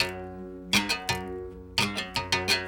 32 Berimbau 03.wav